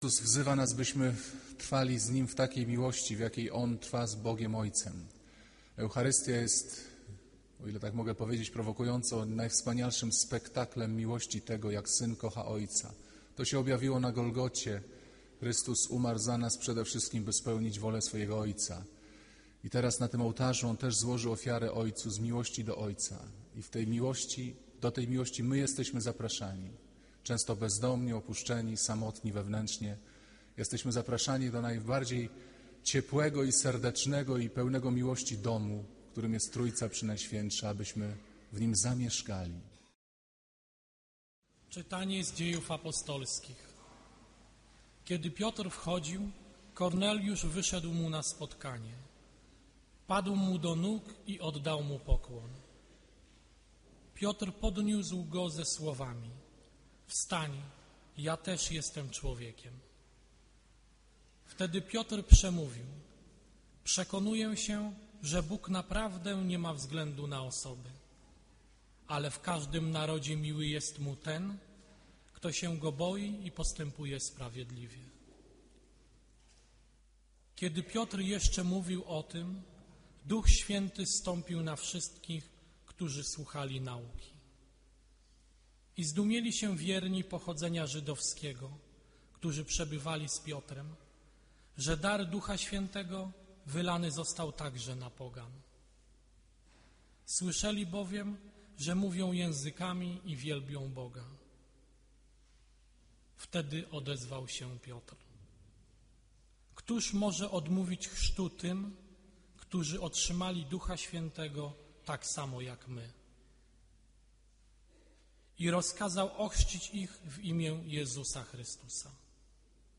Kazania księdza Pawlukiewicza o prawdziwej miłości.
Ksiądz Piotr Pawlukiewicz z pasją i szczerością rozprawia się z naszymi fałszywymi wyobrażeniami o miłości. Bo według Ewangelii – nie chodzi o to, by po prostu się „miłować”, ale by kochać tak, jak Jezus: z prawdą, bezwarunkowo, aż do końca.